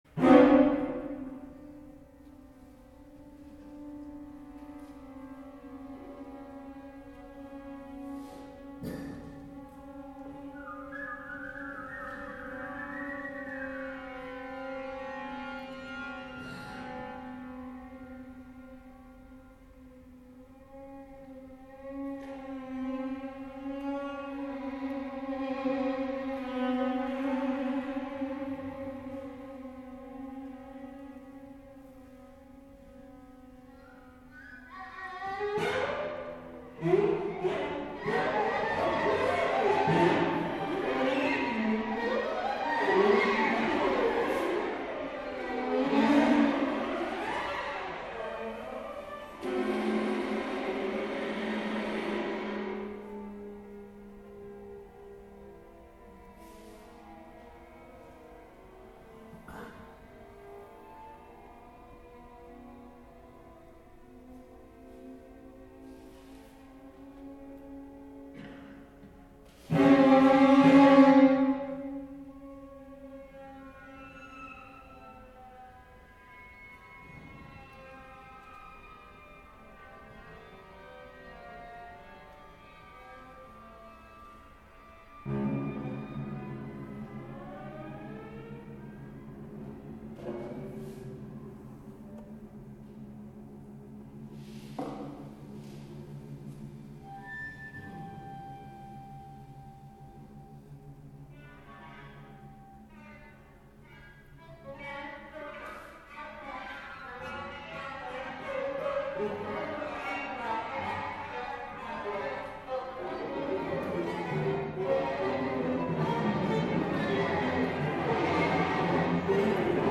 posílám Vám krátkou odezvu na koncert komorní hudby v Zrcadlové kapli Klementina v sobotu 4.1.2020:
bylo to vynikající, dlouho jsem neslyšel tak rozmanitý a přitom kompaktní komorní koncert s výbornou dramaturgií a skvělými hráčskými výkony.